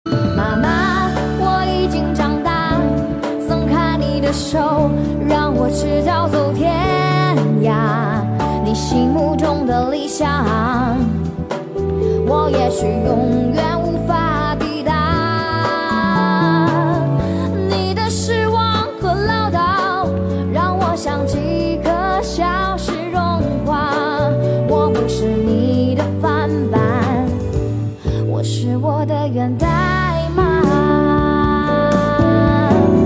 M4R铃声, MP3铃声, 华语歌曲 116 首发日期：2018-05-15 06:29 星期二